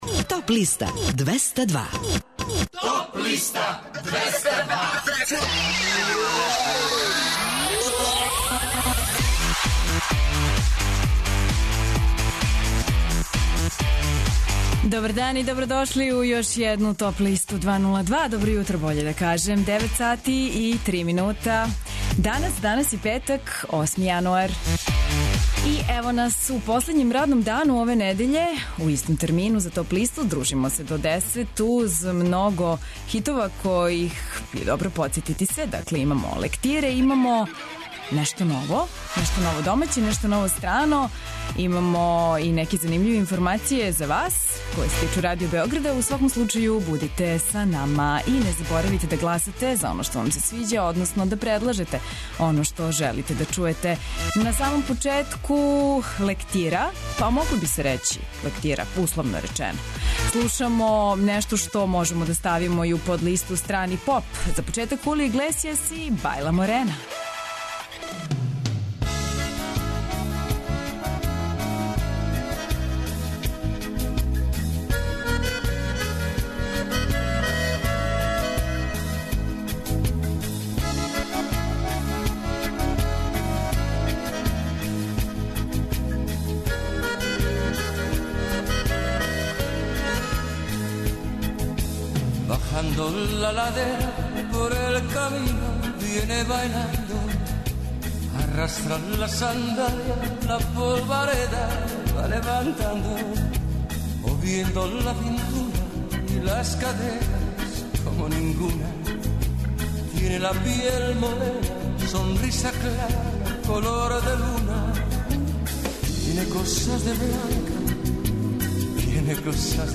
У овонедељном издању Топ листе у 2016. години слушајте новогодишње и божићне музичке нумере, иностране и домаће новитете, као и композиције које су се нашле на подлисти лектира, класика, етно, филмскe музикe...